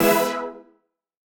Index of /musicradar/future-rave-samples/Poly Chord Hits/Straight
FR_PHET[hit]-E.wav